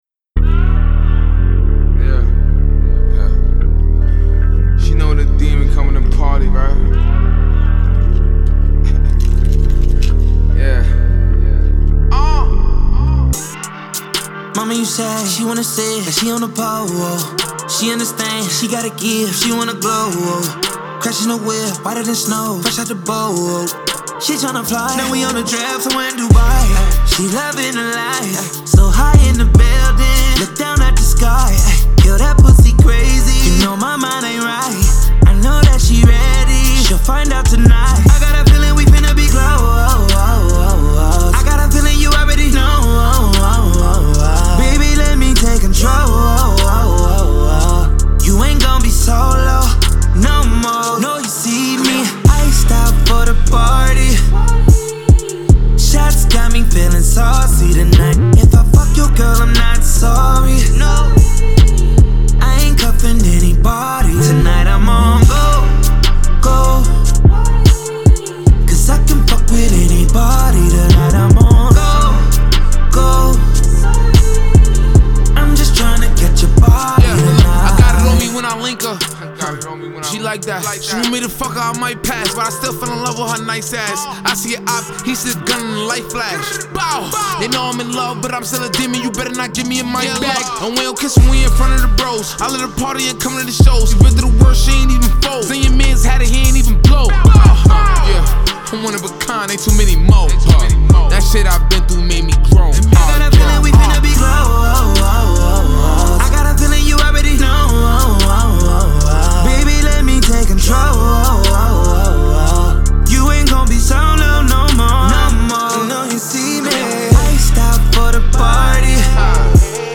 Genre : Rap, Pop